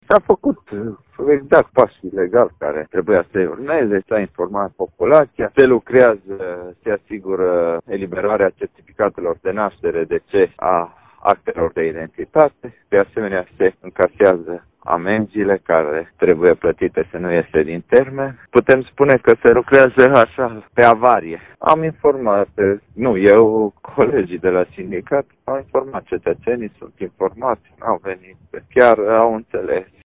Primarul din Târnăveni, Sorin Megheșan.